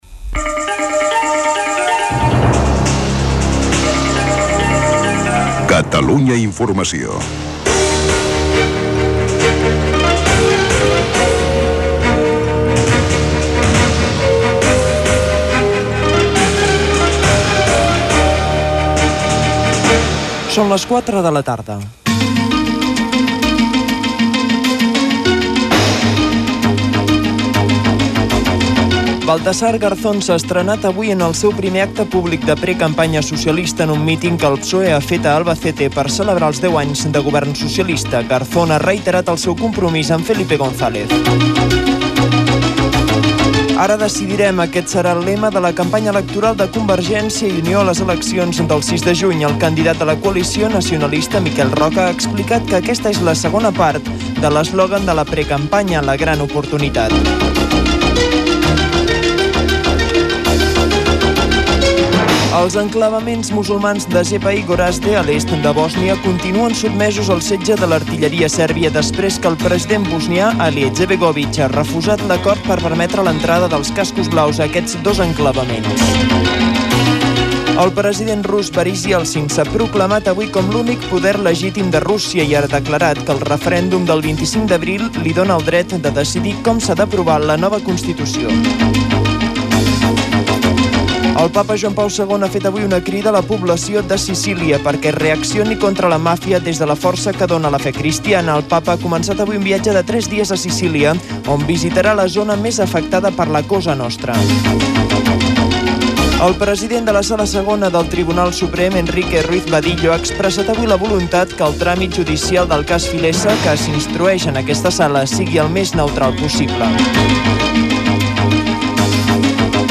Butlletí de notícies. Identificació de l'emissora, hora.
Informatiu
FM